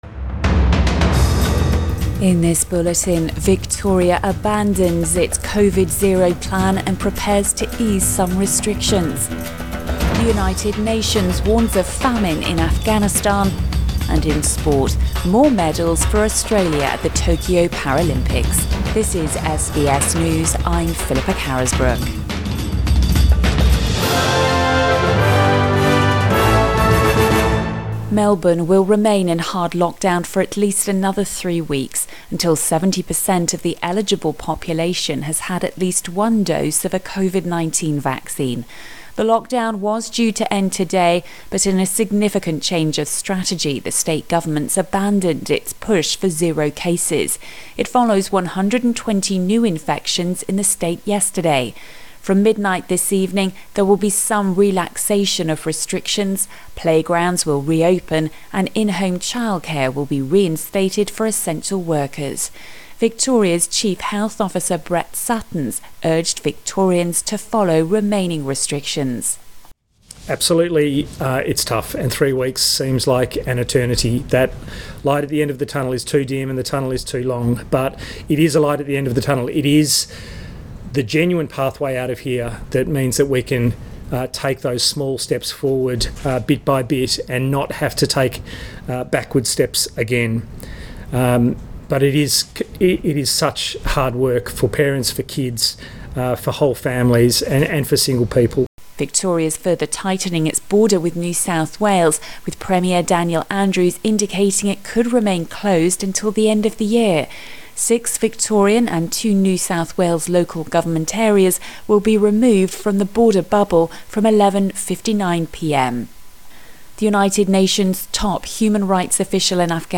AM bulletin 2 September 2021